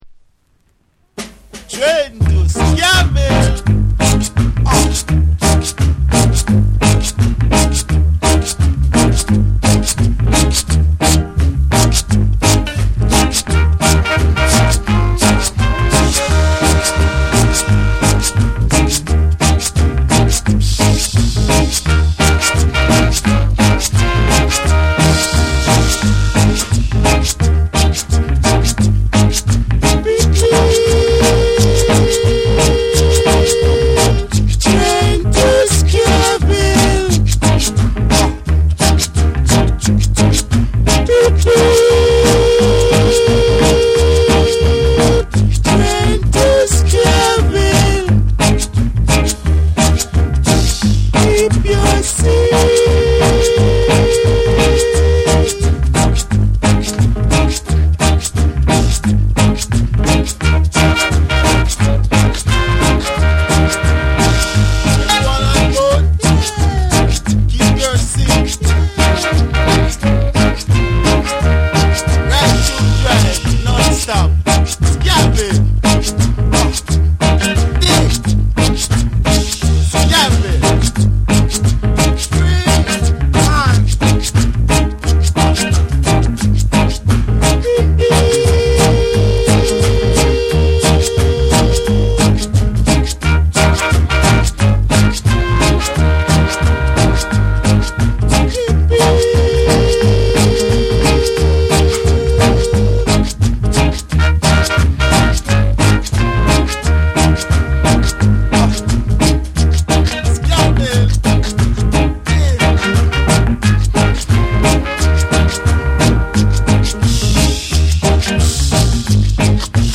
SOUL & FUNK & JAZZ & etc / REGGAE & DUB / TECHNO & HOUSE